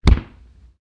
fall.ogg.mp3